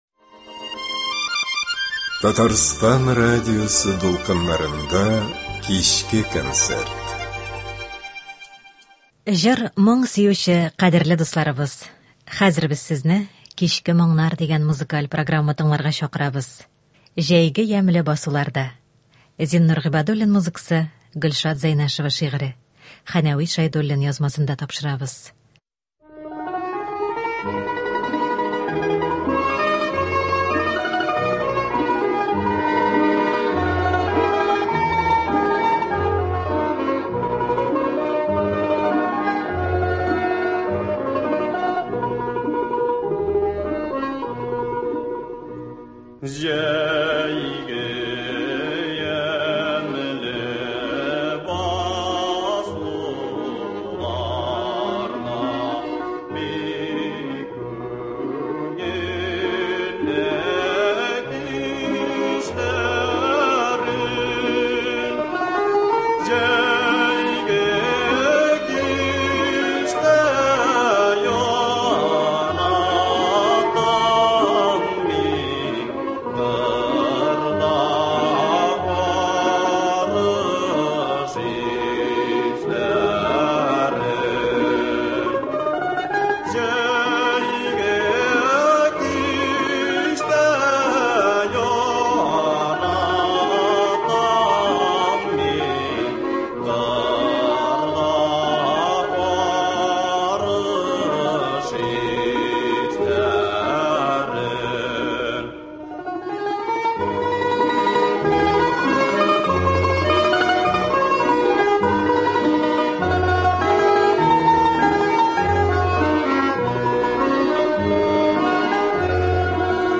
Кичке концерт.